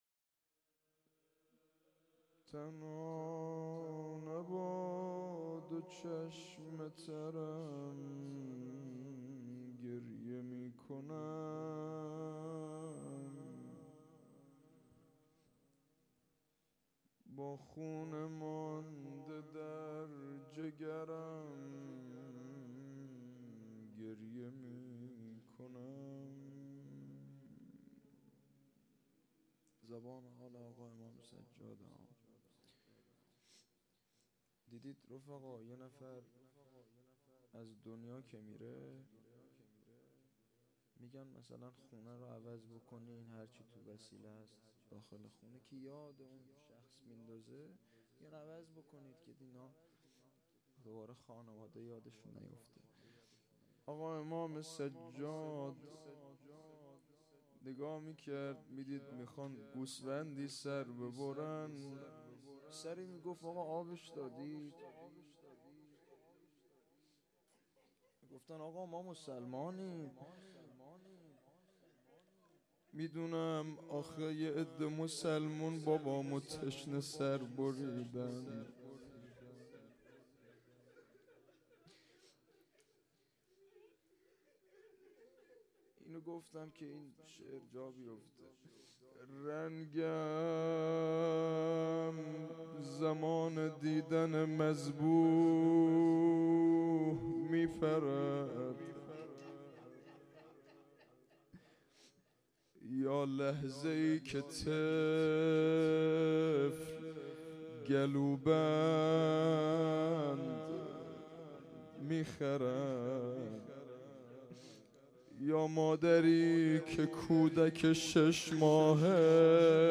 روضه | شهادت امام سجاد(ع)